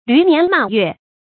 驢年馬月 注音： ㄌㄩˊ ㄋㄧㄢˊ ㄇㄚˇ ㄩㄝˋ 讀音讀法： 意思解釋： 不可知的年月。